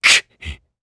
Lusikiel-Vox_Sad_jp.wav